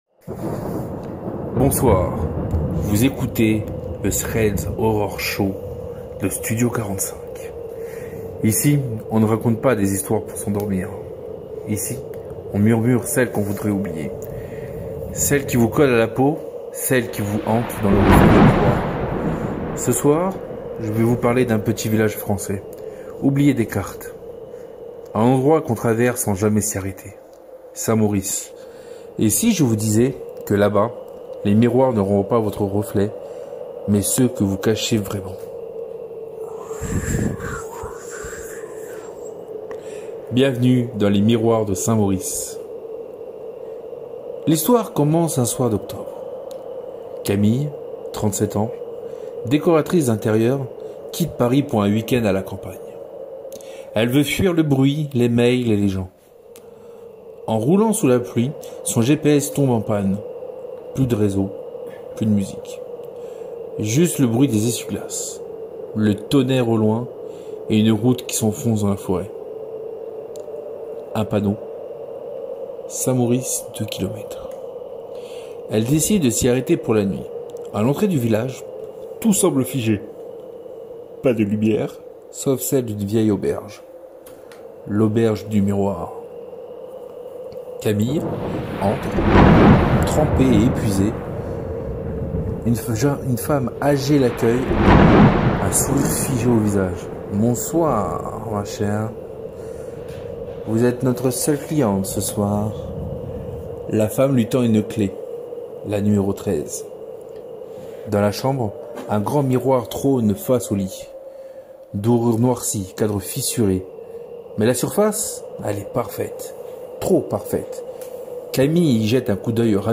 Au programme : Des histoires originales ou revisitées, pensées pour l’occasion, qui jouent avec les mythes, les légendes urbaines et les peurs ancestrales. Une ambiance sonore immersive, des voix à glacer le sang, et des effets qui font frissonner.